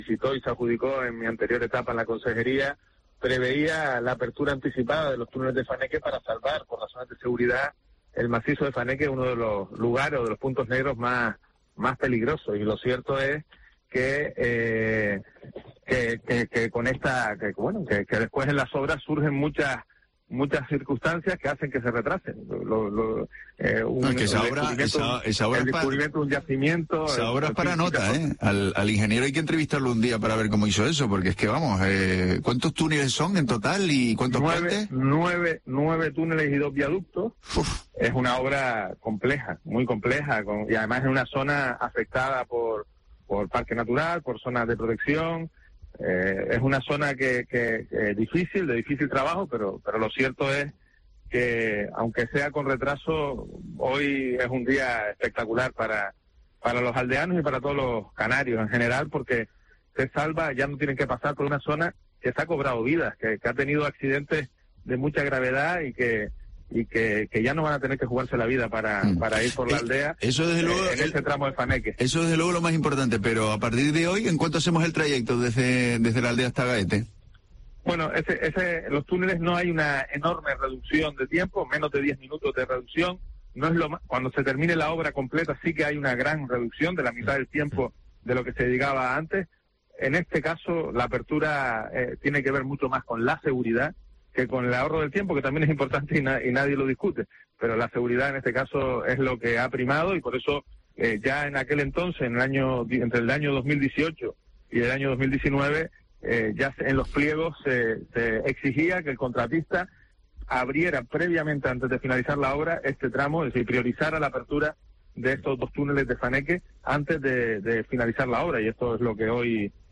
Entrevista a Pablo Rodríguez, consejero de Obras Públicas del Gobierno de Canarias